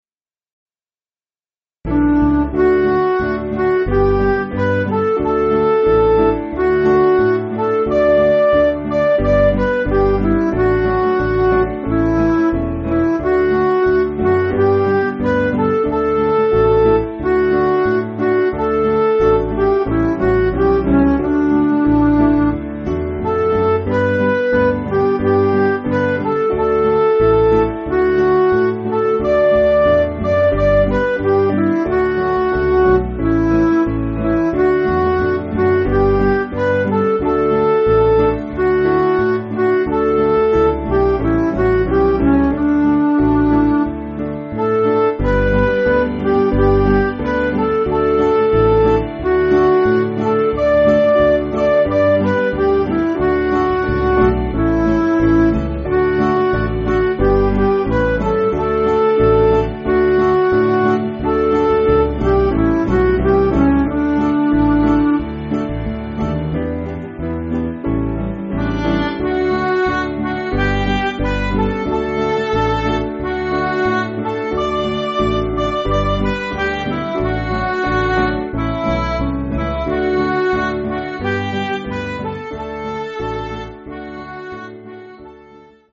Hymn books
Piano & Instrumental